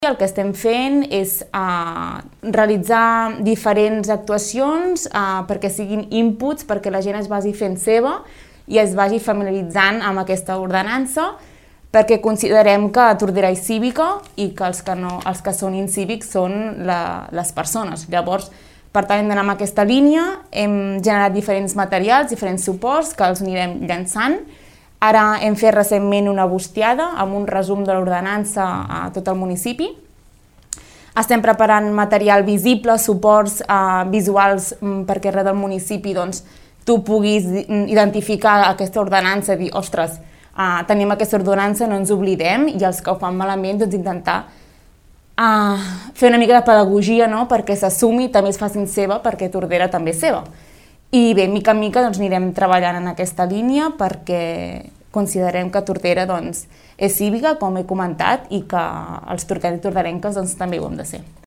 En parla la regidora de civisme, Bàrbara Vergés.